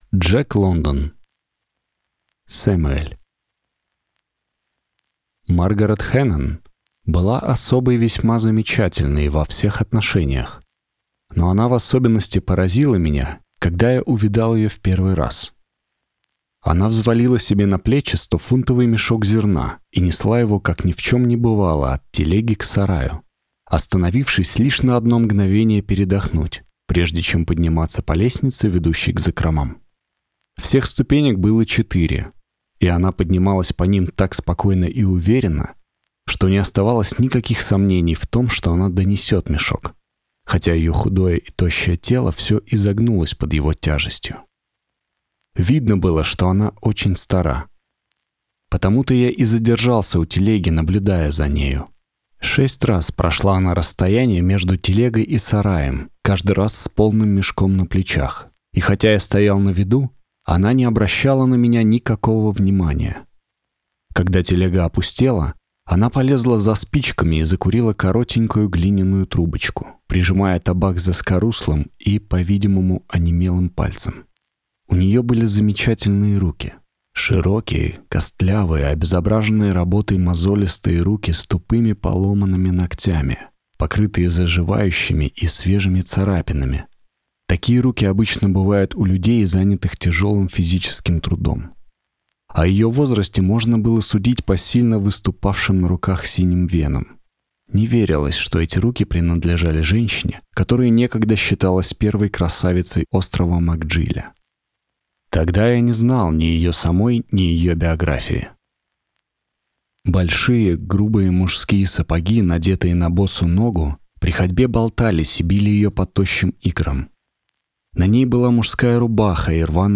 Аудиокнига Самуэль | Библиотека аудиокниг